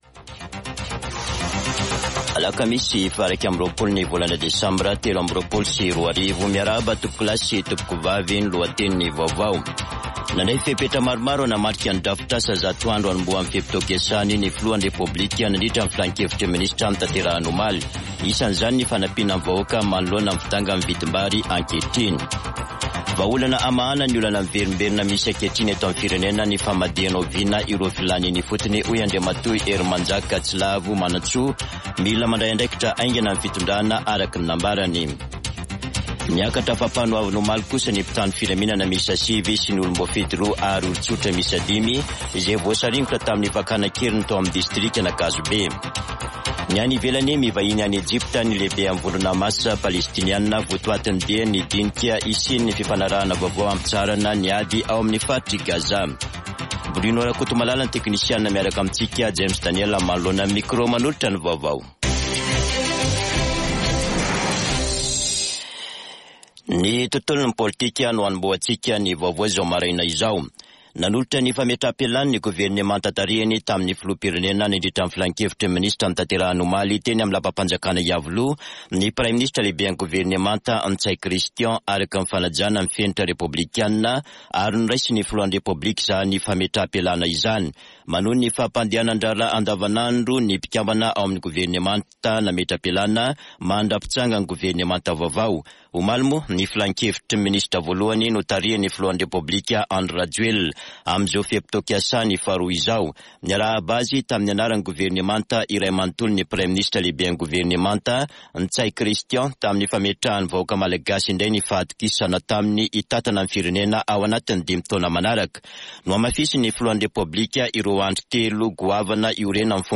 [Vaovao maraina] Alakamisy 21 desambra 2023